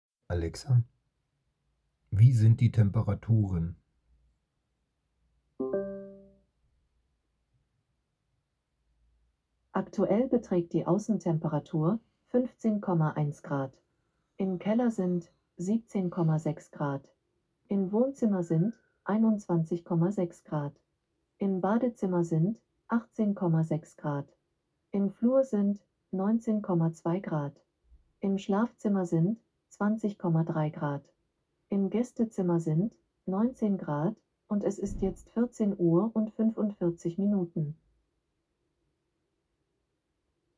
Fenster und andere Geräte zählen opt. Alexa Ansagen